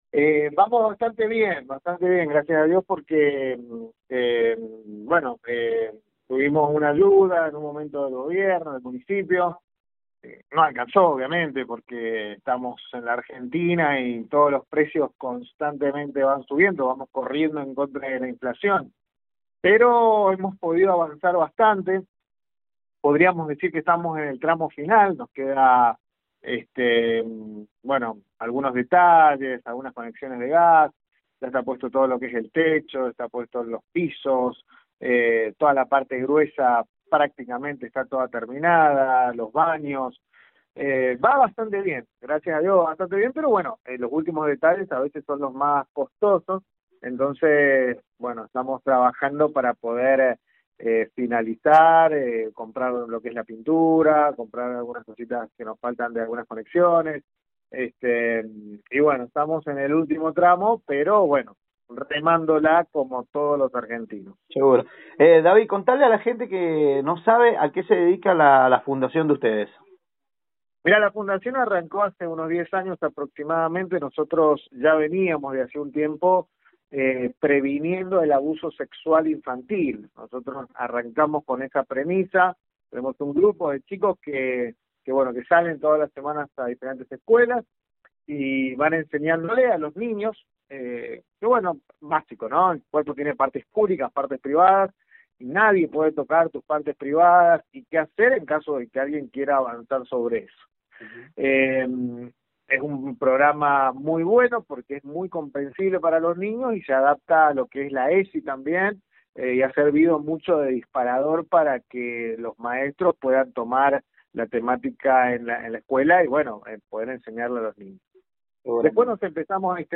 De ahí en adelante, un largo recorrido ayudando y acompañando a los menores, que se encuentra detallado en el audio de la entrevista. La Feria con objetivo solidario, es para este próximo sábado en horas de la tarde.